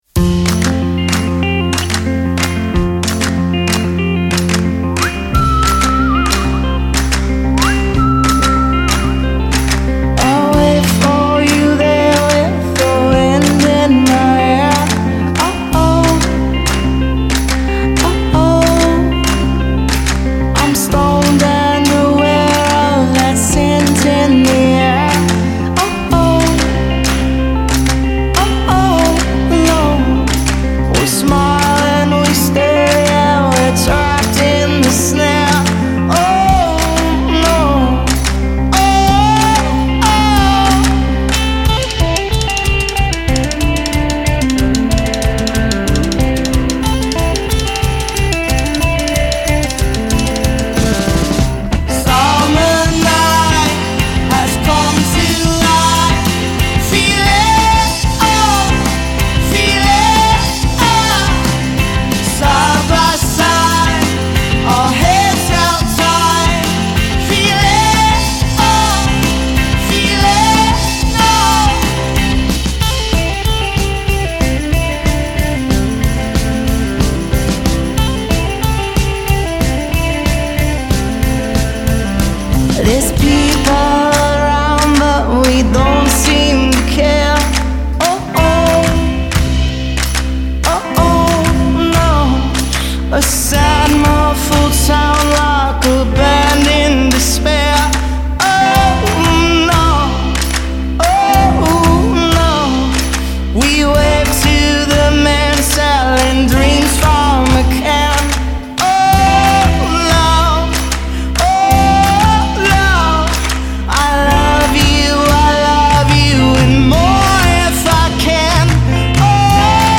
poppy